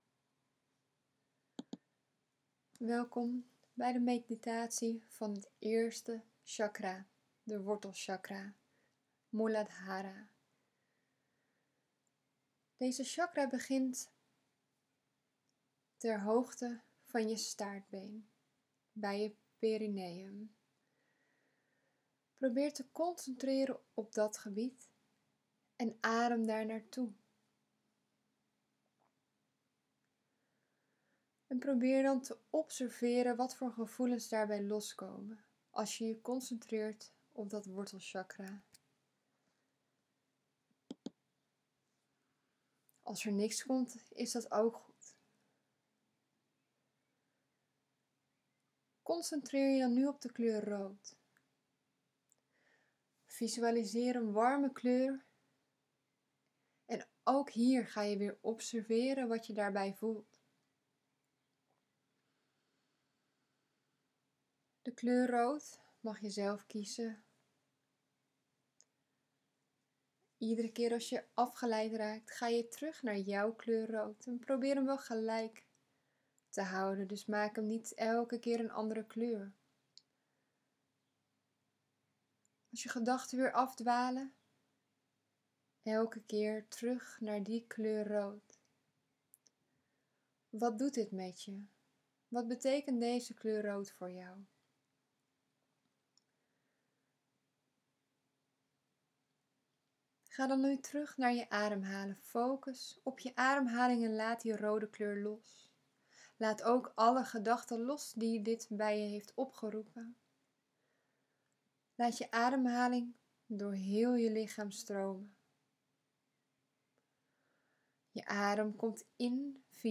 Meditatie